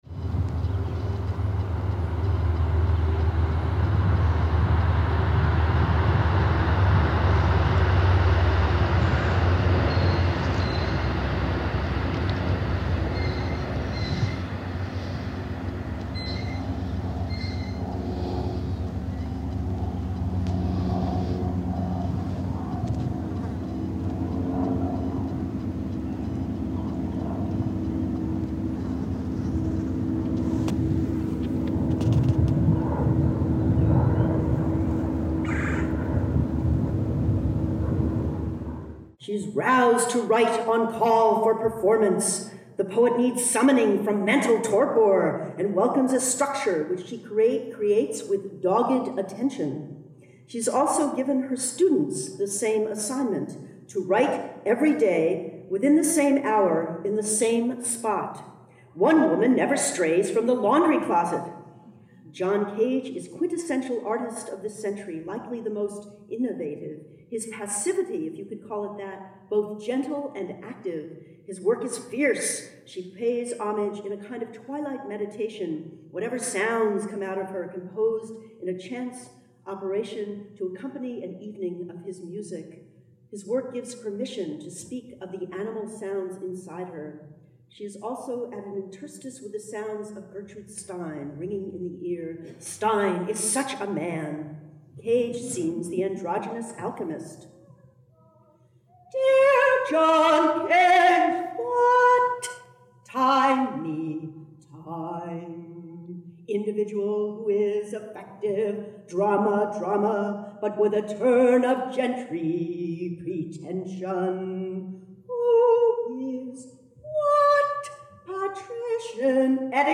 Unearthing a recording of a 1996 performance she gave in Albany, this month’s program celebrates the work of Anne Waldman.
Blending words and sounds, bringing to the airwaves live performances as well as field and studio recordings by writers the host has crossed paths with over the course of a quarter century.